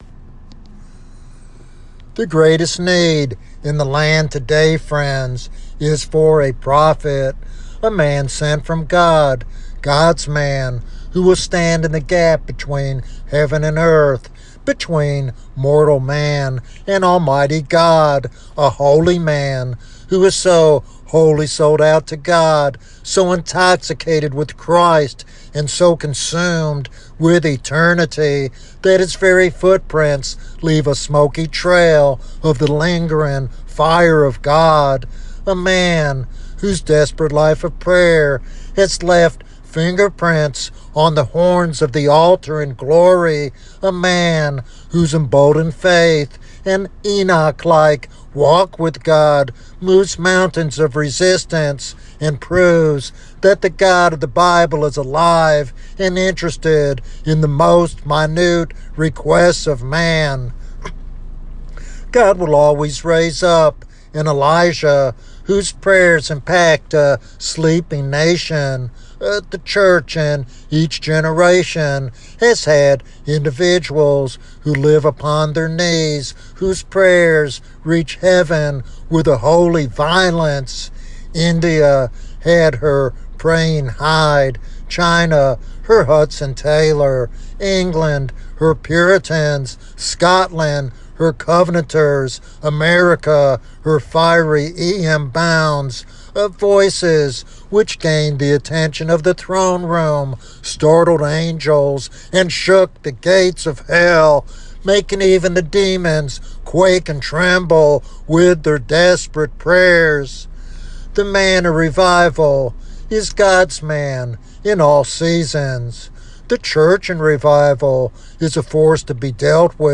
This sermon challenges listeners to pray fervently for revival and to become vessels God can use mightily.